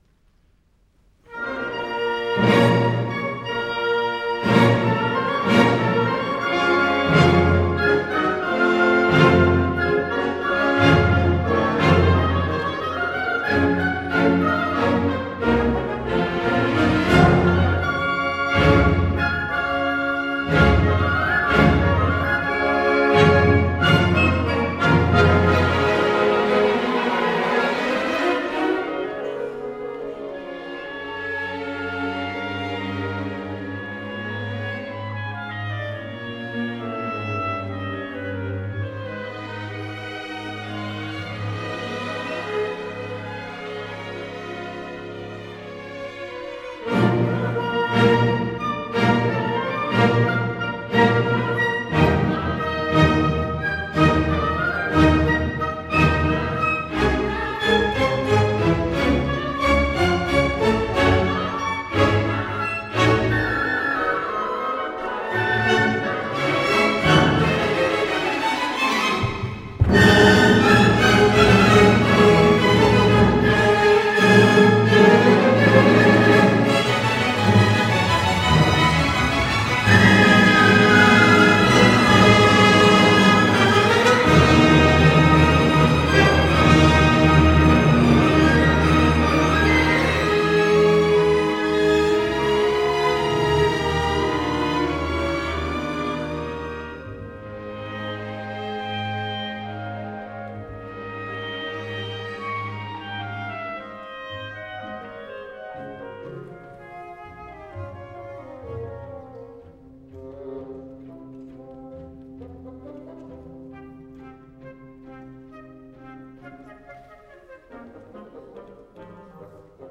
Fritz Brun: II. Allegro appassionato